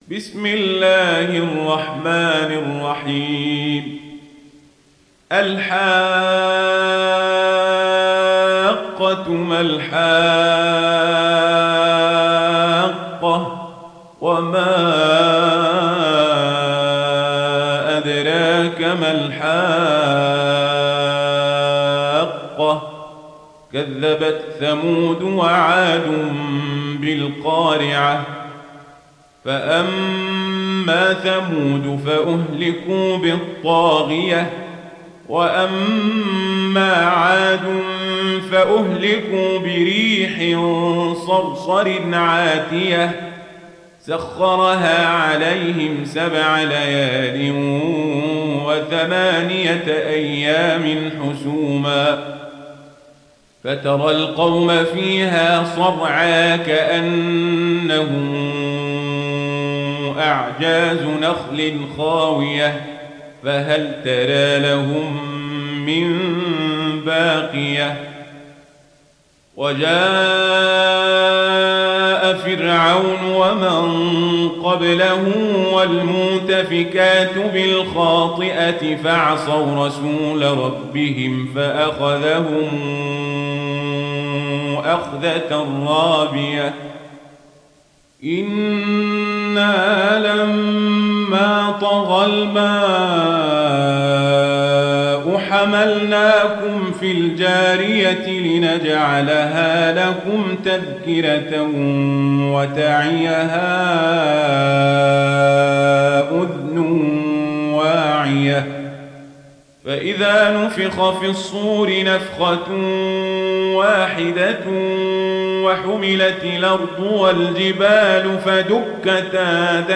تحميل سورة الحاقة mp3 بصوت عمر القزابري برواية ورش عن نافع, تحميل استماع القرآن الكريم على الجوال mp3 كاملا بروابط مباشرة وسريعة